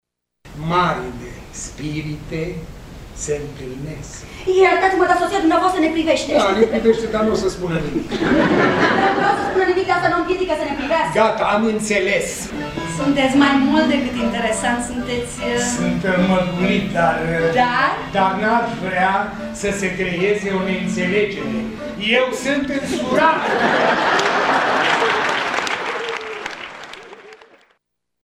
Ascultați un fragment din această piesă în care Florin Piersic joacă alături de Medeea Marinescu: